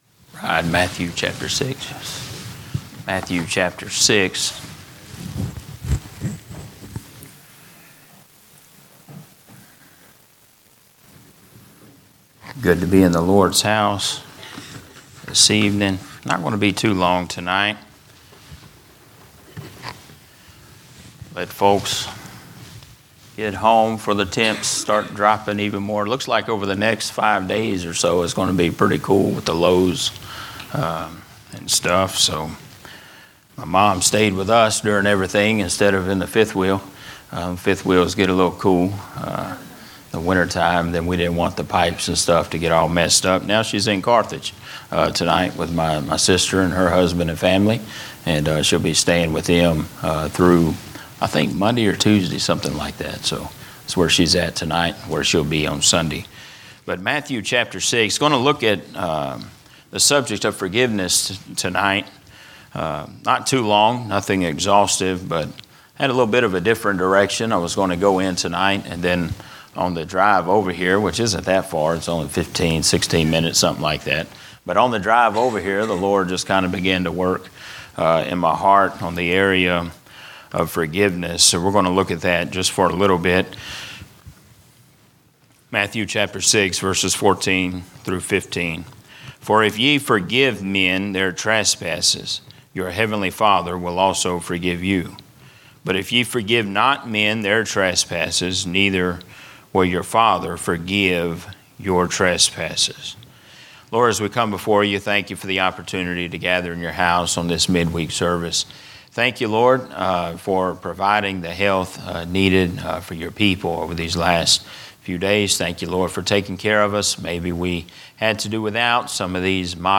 From Series: "General Preaching"